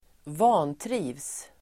Ladda ner uttalet
Uttal: [²v'a:ntri:vs]
vantrivs.mp3